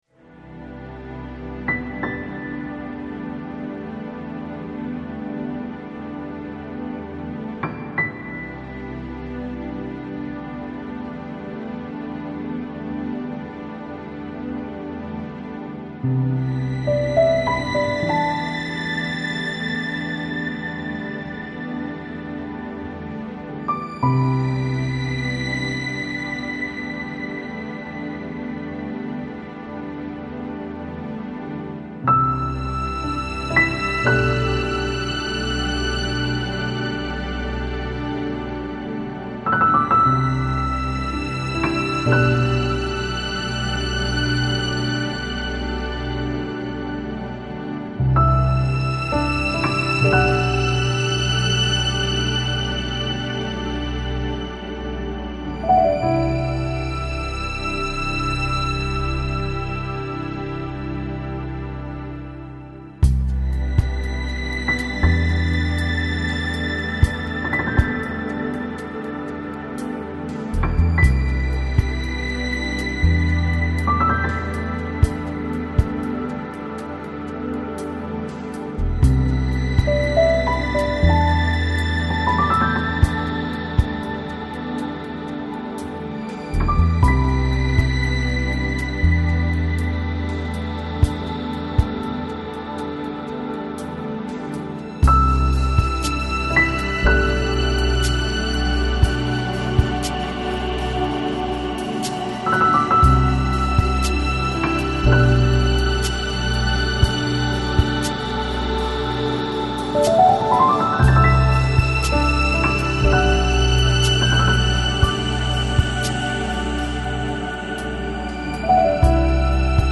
Жанр: Balearic, Downtempo